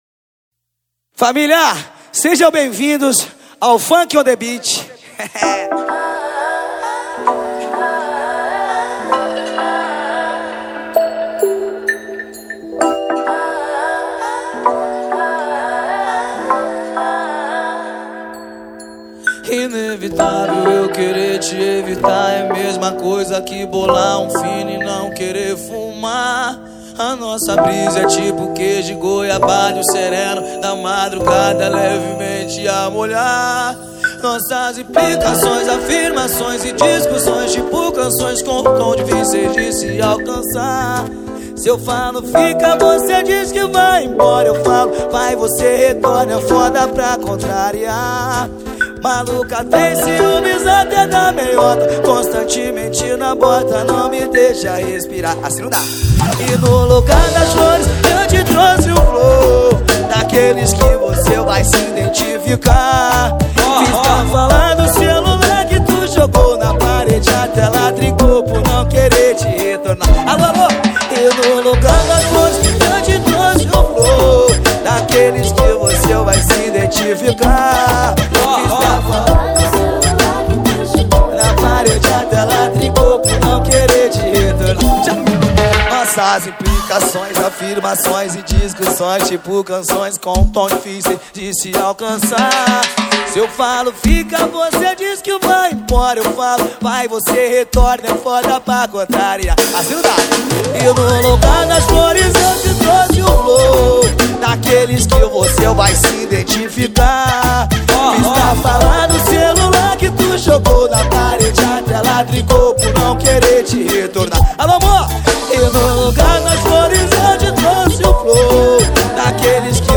2024-12-21 23:46:22 Gênero: Funk Views